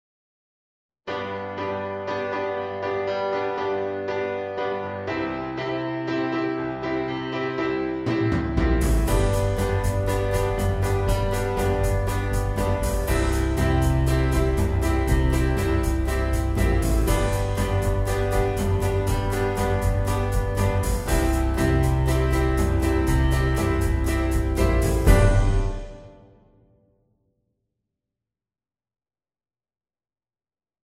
Micro-Warm-up-G.mp3